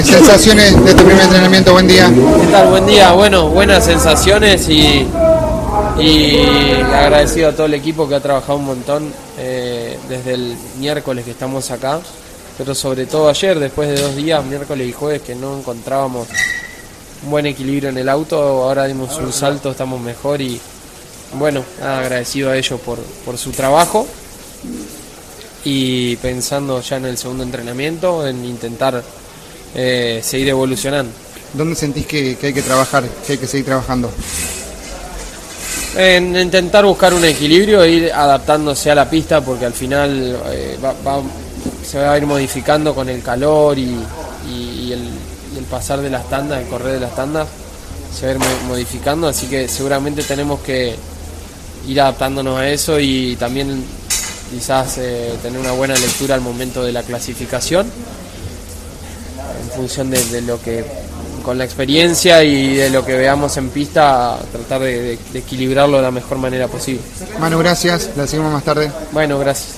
La palabra de José Manuel Urcera en exclusiva para CÓRDOBA COMPETICIÓN: